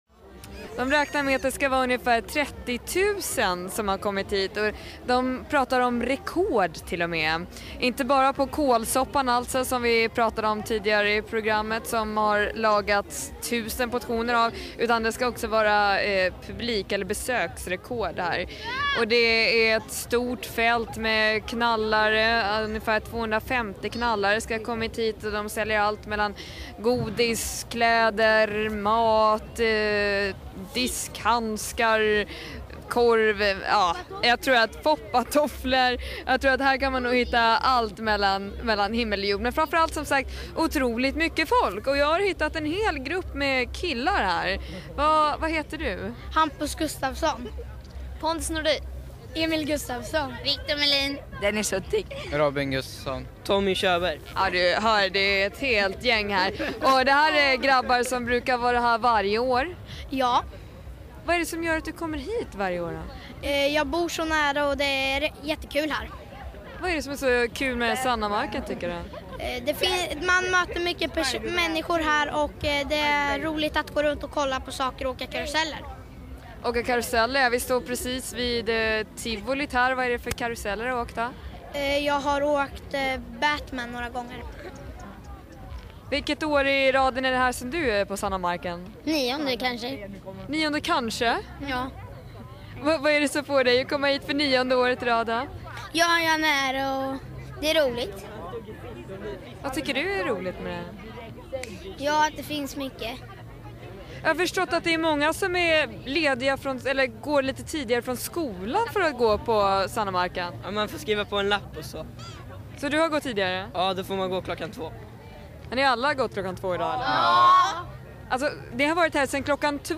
Radio Örebro besöker Sannamarken 2008 >>